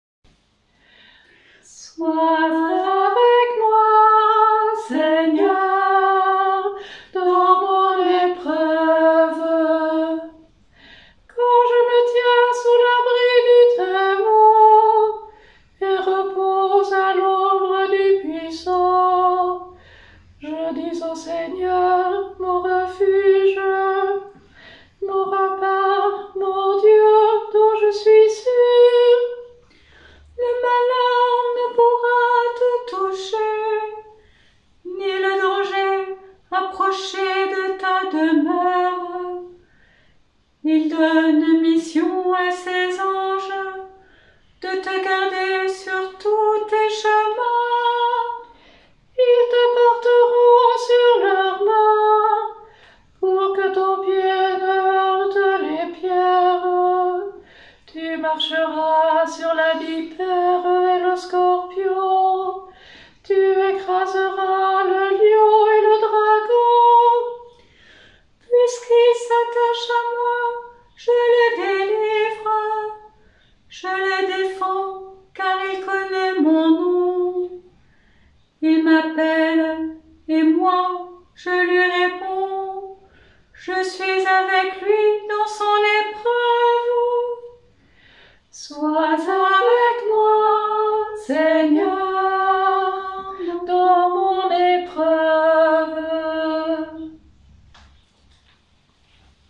Vous trouverez ci-dessous les enregistrements de ces psaumes, avec les musiques de différents compositeurs, et des mises en œuvre sur 2 stiques (le verset est chanté sur une intonation de deux lignes) ou 4 stiques (le verset est chanté sur une intonation de quatre lignes)
1er dimanche de Carême : Psaume 90